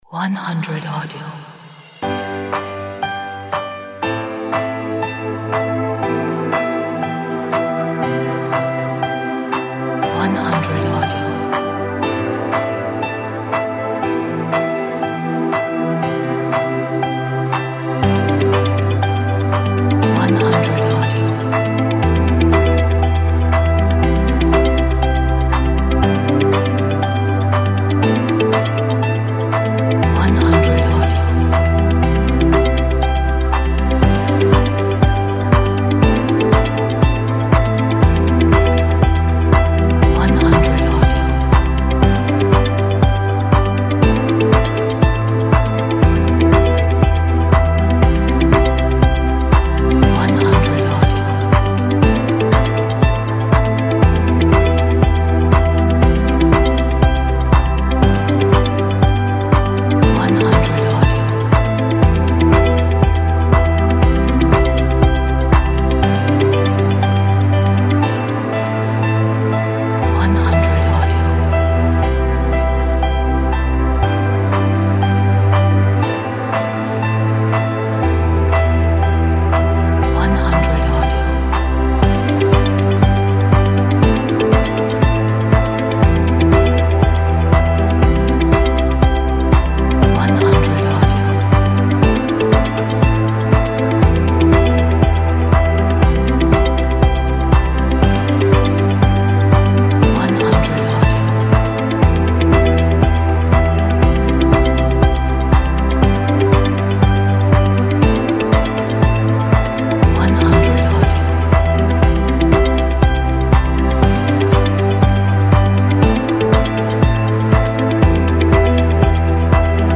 Deep soulful ambient track!